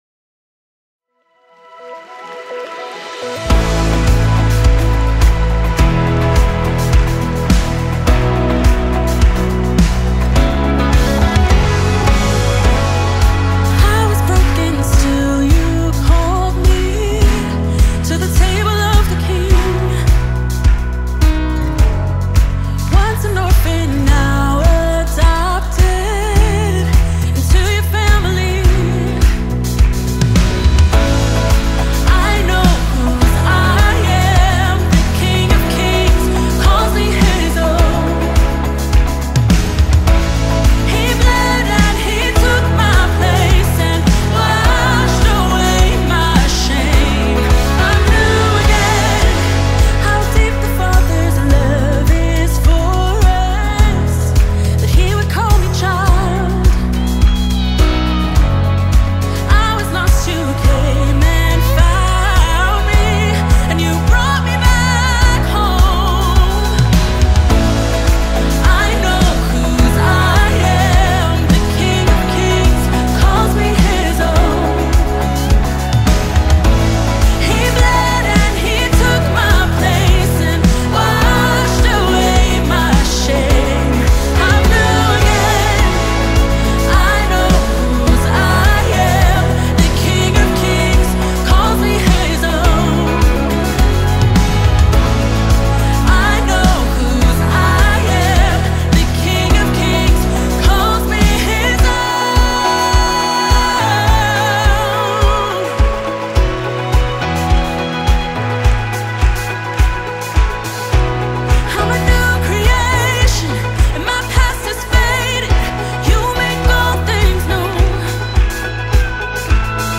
песня
2025 live альбом